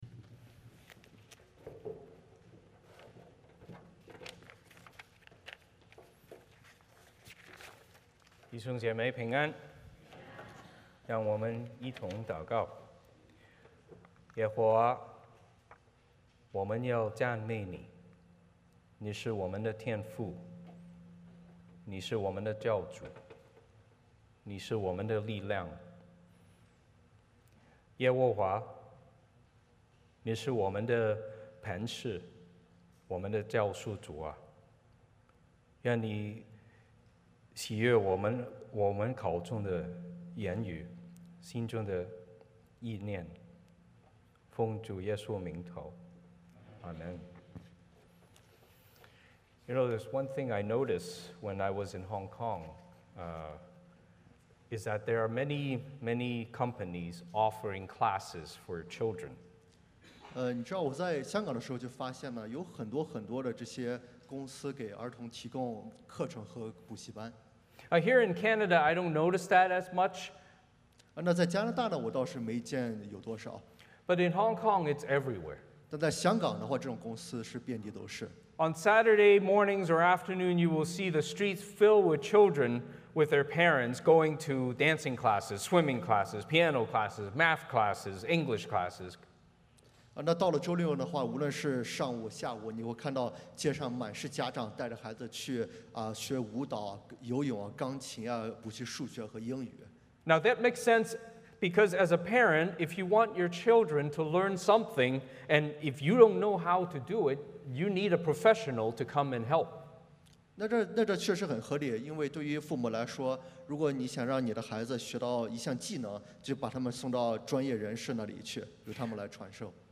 欢迎大家加入我们国语主日崇拜。